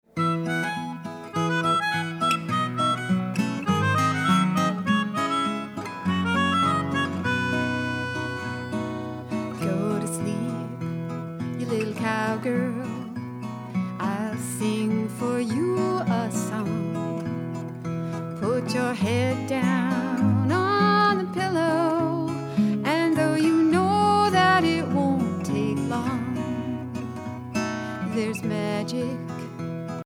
Lullabies from Then, Now and Forever